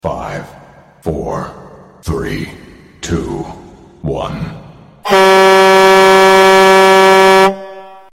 stop countdown Meme Sound Effect
stop countdown.mp3